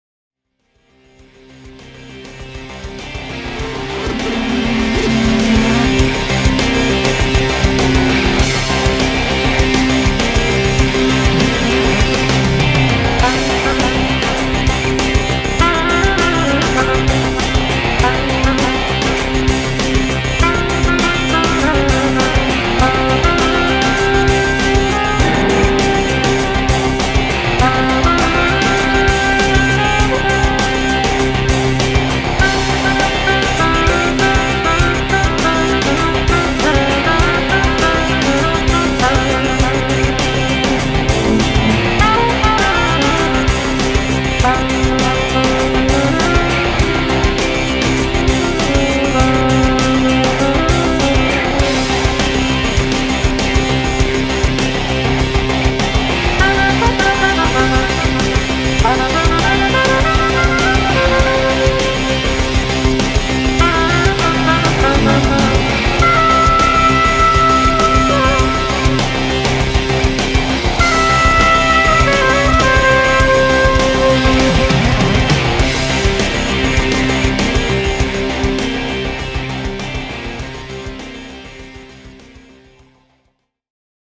instrumental -